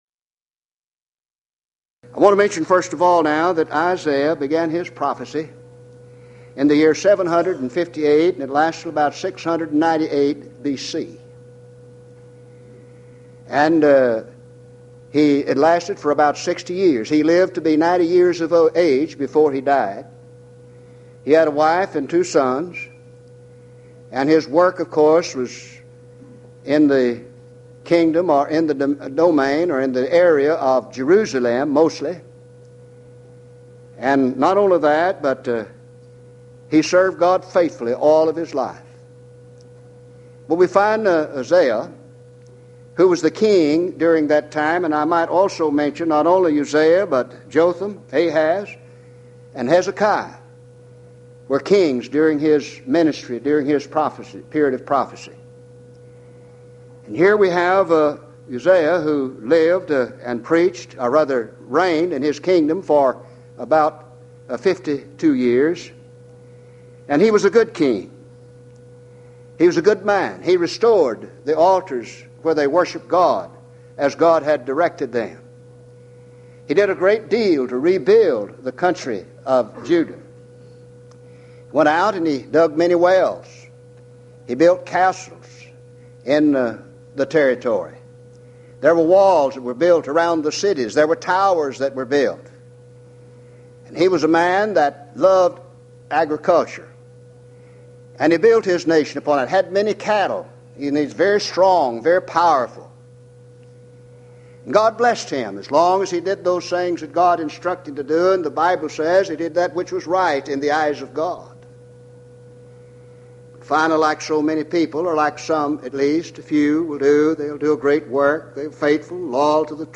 Houston College of the Bible Lectures